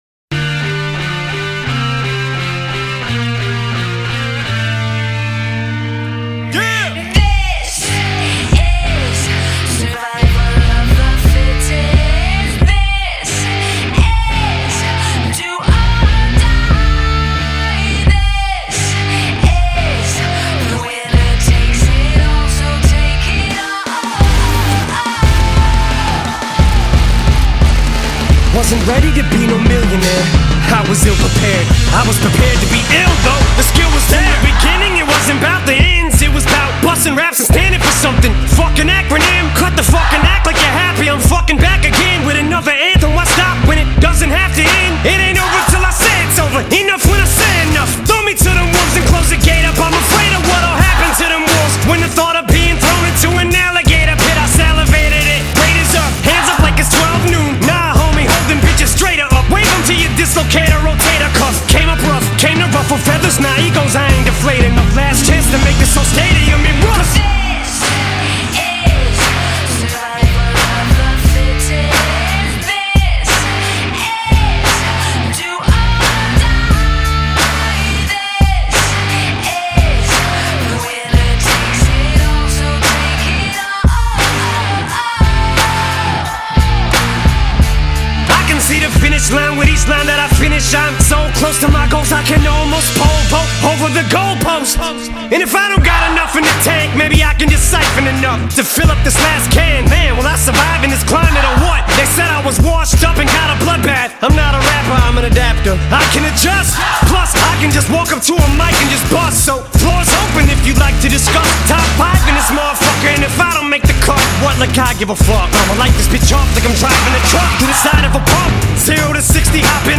rock-themed track